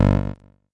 卡西欧VL Tone VL1 " ADSR G Low 07 - 声音 - 淘声网 - 免费音效素材资源|视频游戏配乐下载
我的采样卡西欧VLTone VL1系列中的一部分ADSR编程贝司，G调短低槽复古